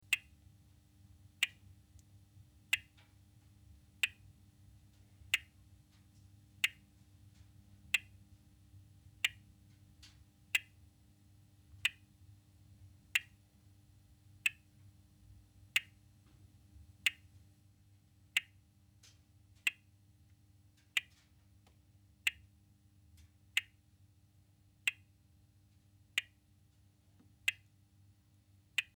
На этой странице собраны звуки капельницы в разных вариантах: от монотонного стекания жидкости до фонового шума больничной палаты.